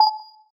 pause-continue-click.mp3